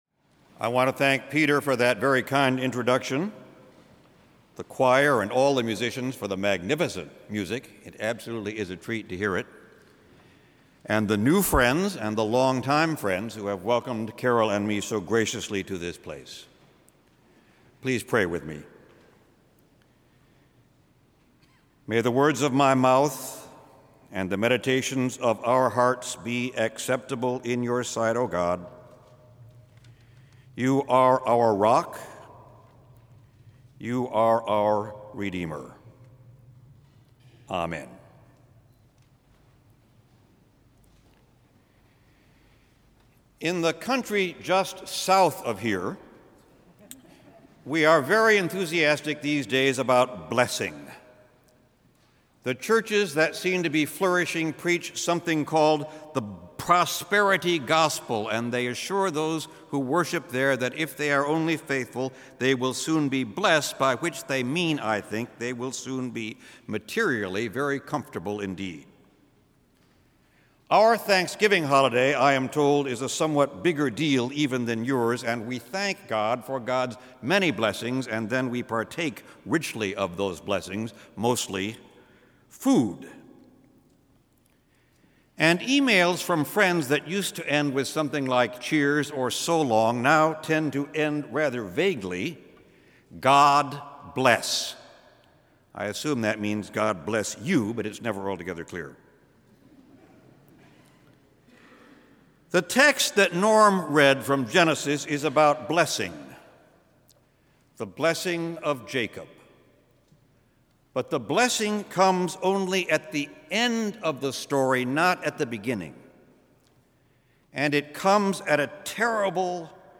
LRPF SPEAKERS – THE EARLY YEARS These days, when words are cheap. and cheaply thrown around, great preaching is a necessity, a challenge and a God-send.